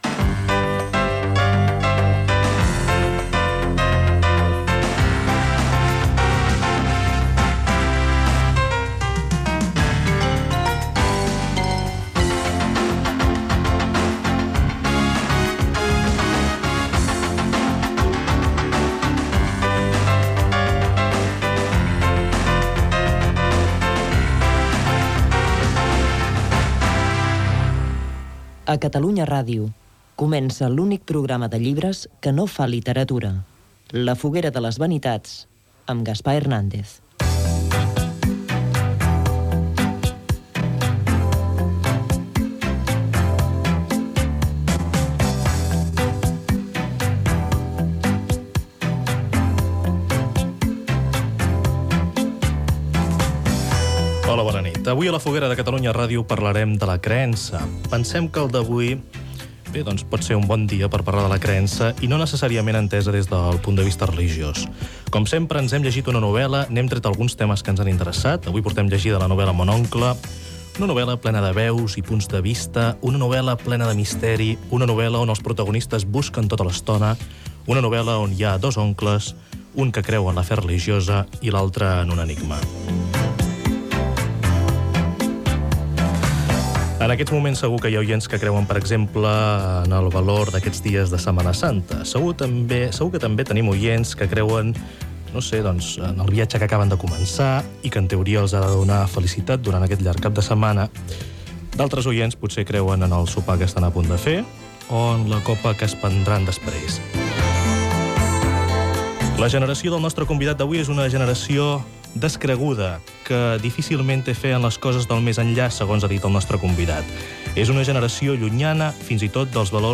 Indicatiu de l'emissora, careta del programa, presentació del programa dedicat a "la creença" amb una entrevista a Màrius Serra que presenta la novel·la "Mon oncle"